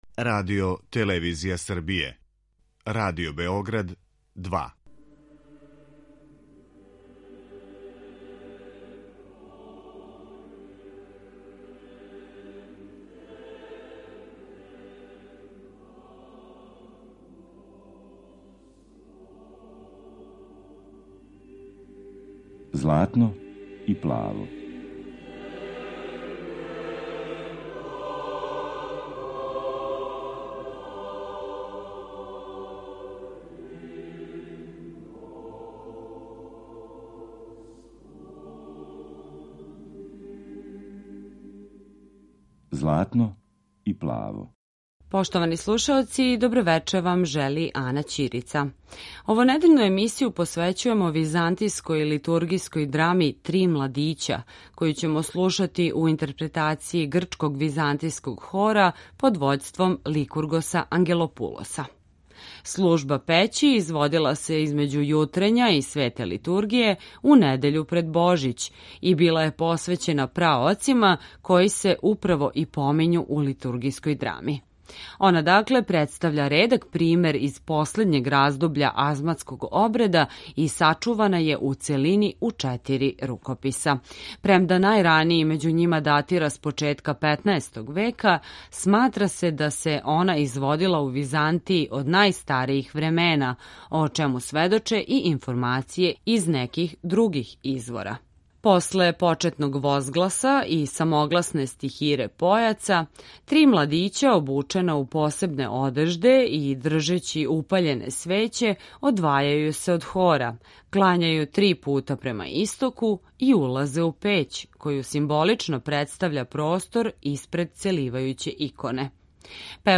Византијска литургијска драма